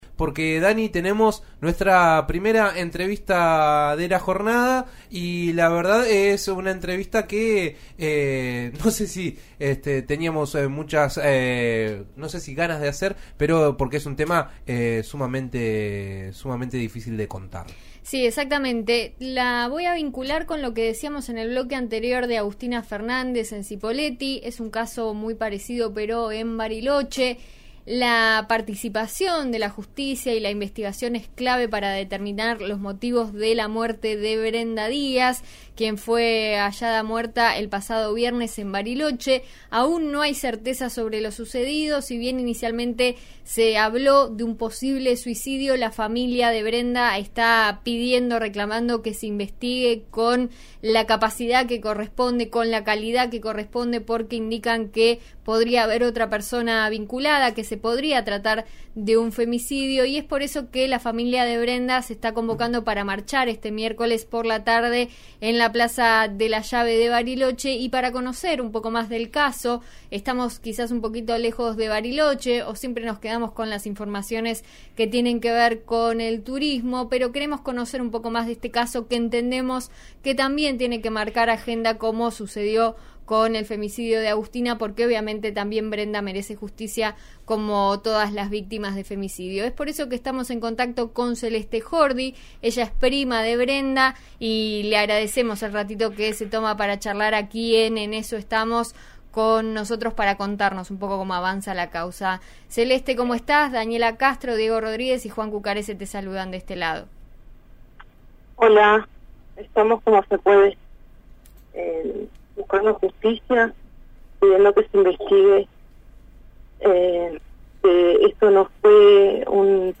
en diálogo con «En eso estamos» por RÍO NEGRO RADIO.